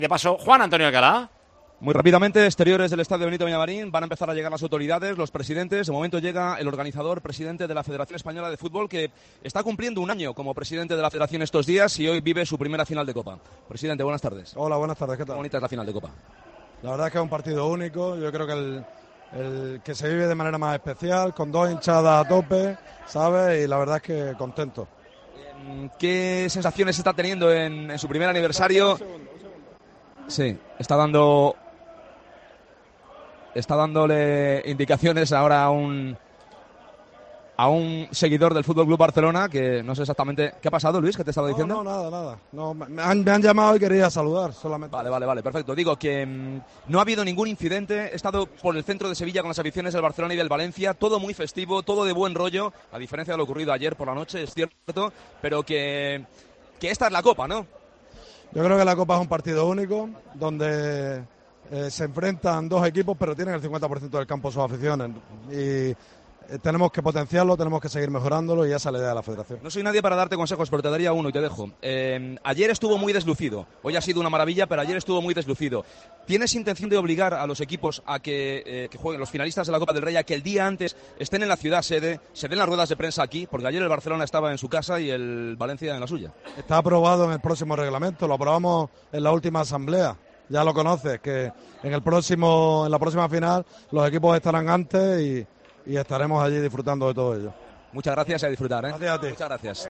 El presidente de la RFEF aseguró en COPE que el año que viene los finalistas tendrán que estar en la ciudad de la final un día antes del partido.